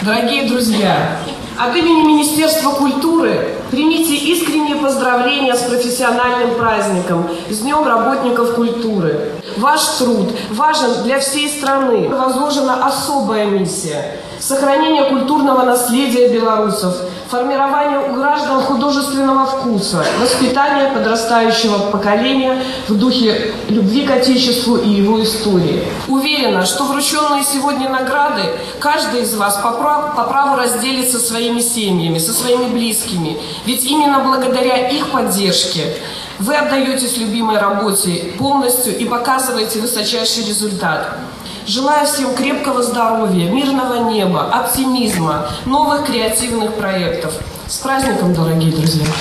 Накануне в Барановичском городском Доме культуры состоялось торжество, на которое были приглашены лучшие представители творческих профессий области.